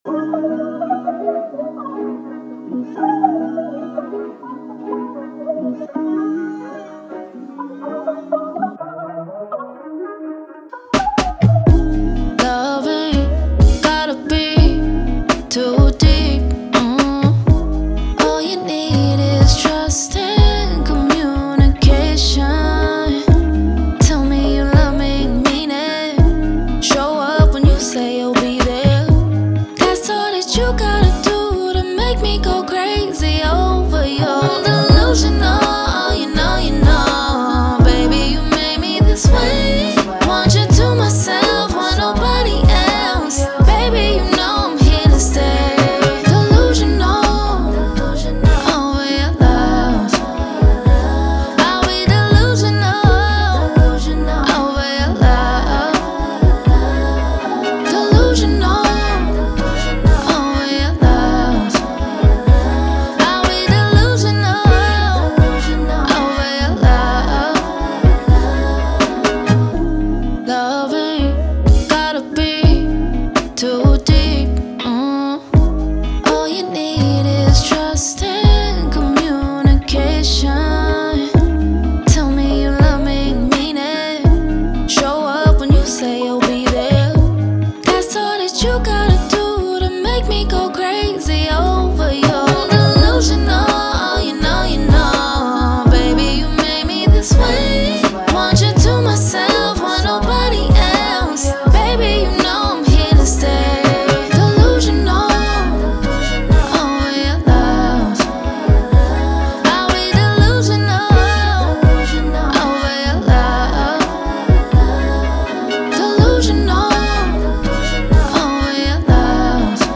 R&B
Abmin